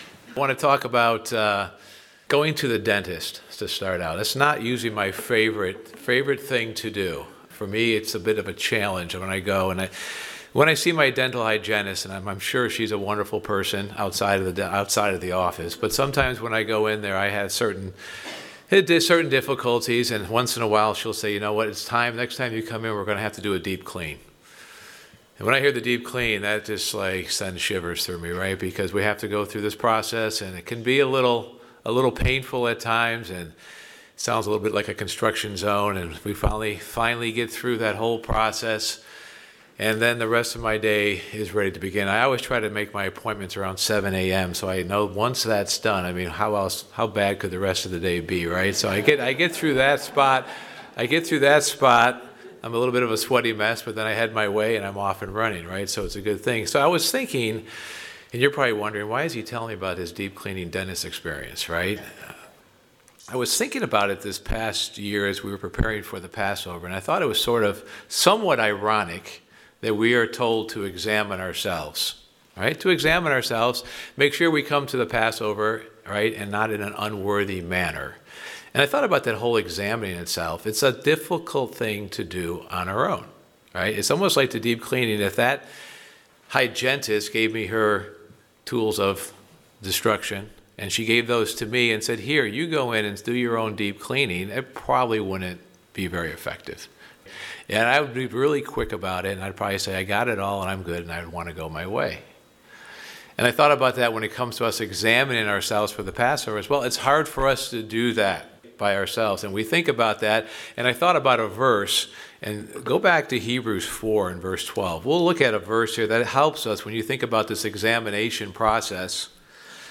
Examining yourself is a difficult thing to do on your own. This sermon reflects on how God does a spiritual deep cleaning on us that we cannot do on our own.